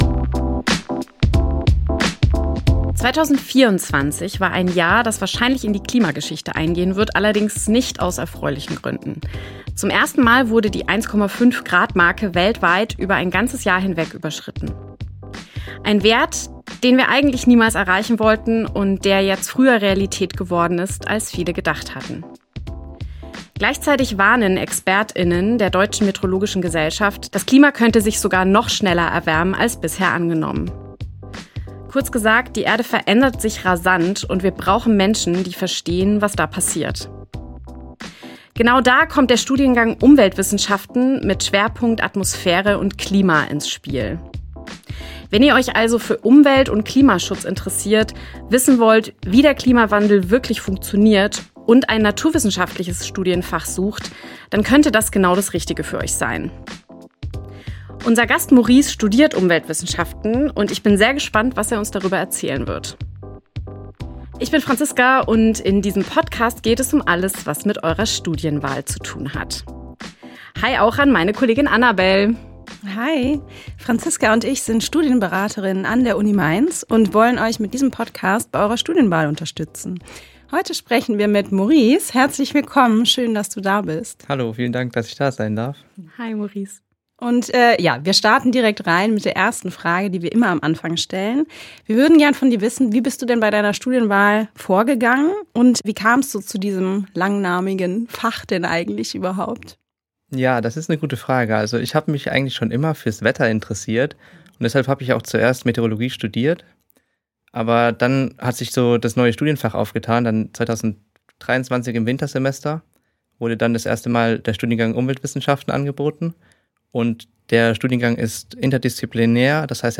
Unser Gast